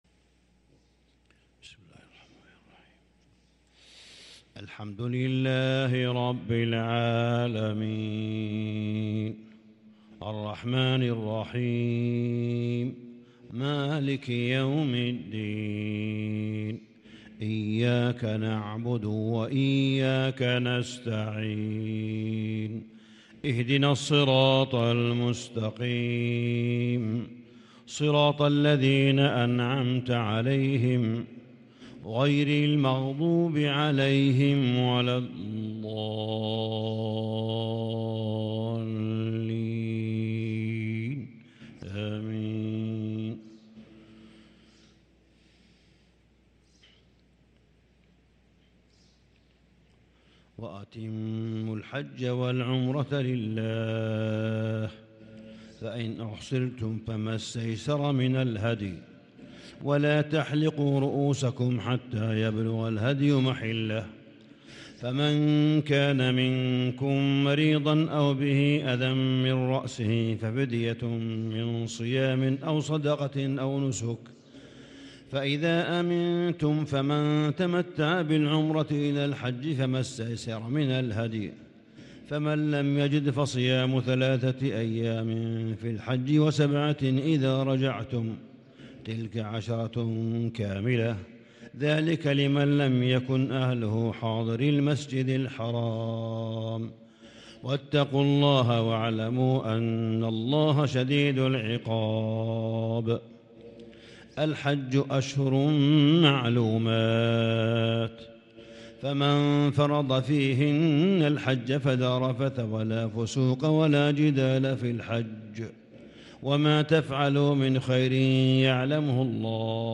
صلاة الفجر للقارئ صالح بن حميد 5 ذو الحجة 1443 هـ